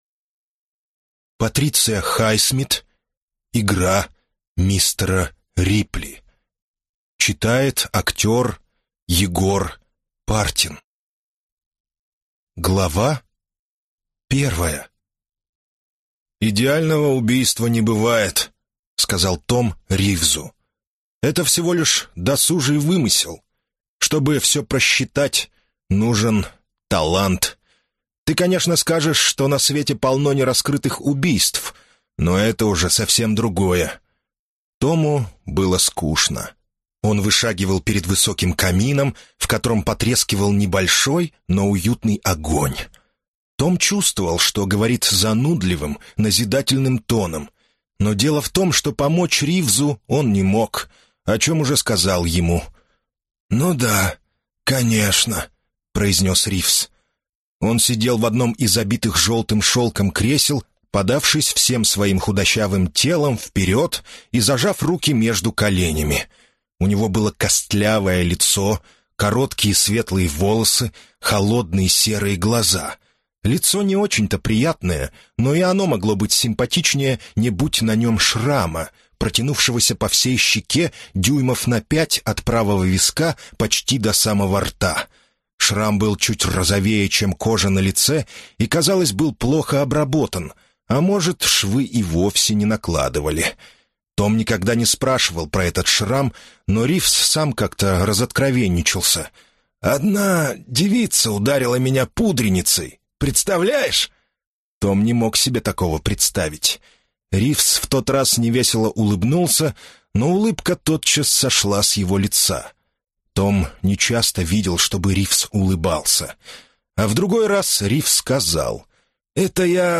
Аудиокнига Игра мистера Рипли | Библиотека аудиокниг